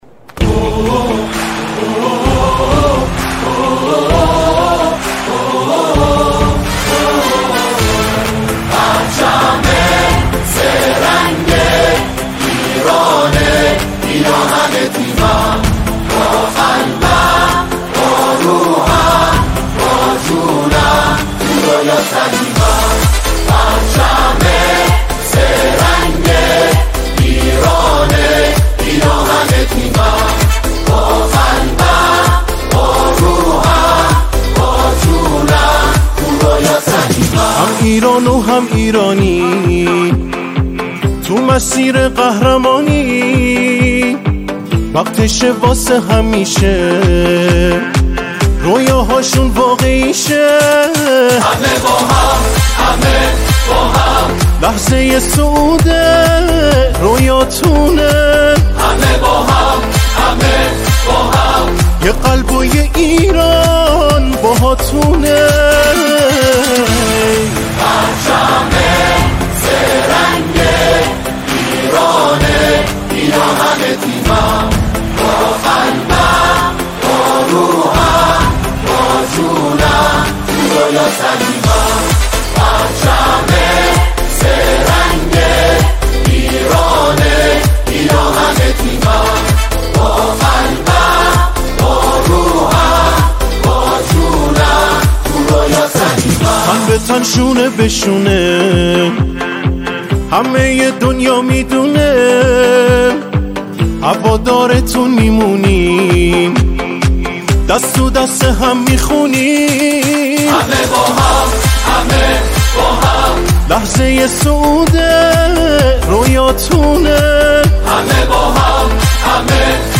سرودهای ورزشی
در این سرود شاد و انرژی بخش
به تک خوانی پرداخته است.